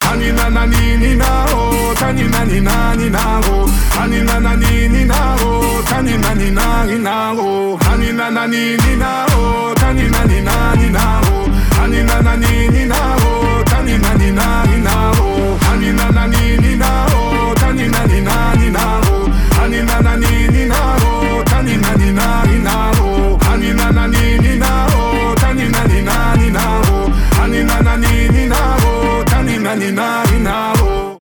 поп
рэп
танцевальные